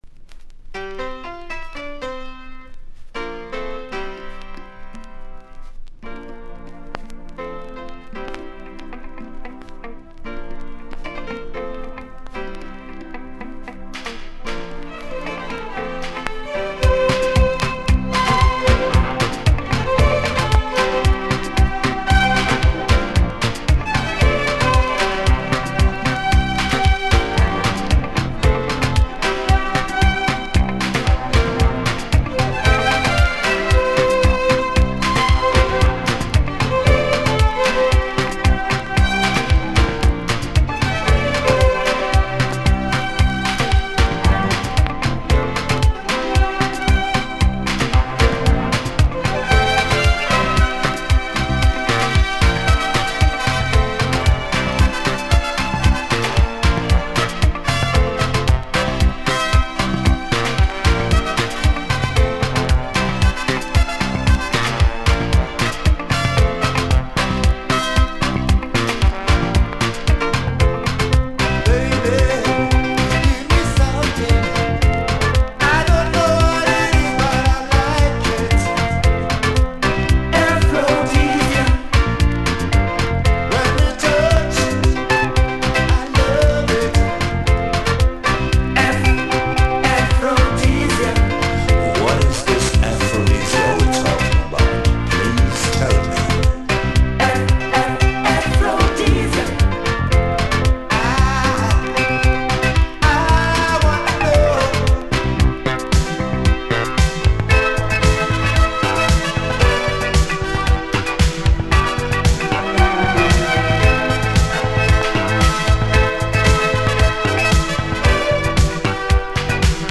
Poppy disco cut